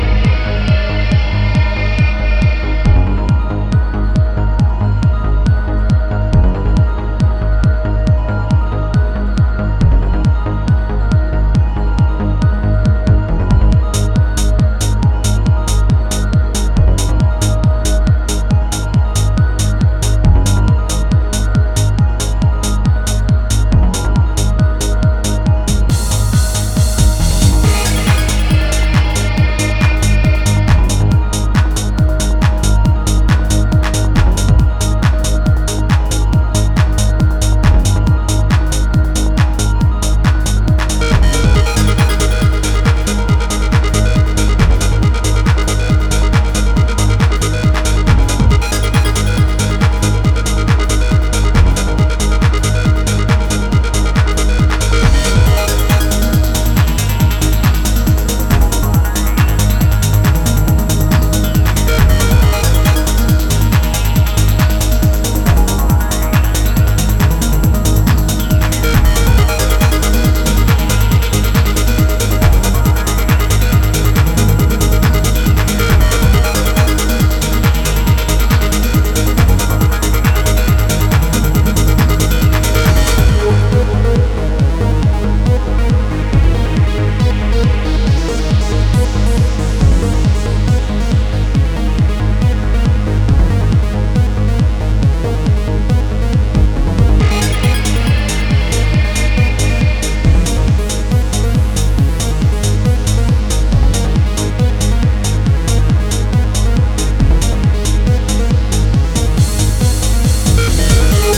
Neo Goa / Progressive Trance tracks
aery Drum’n’bass experimentations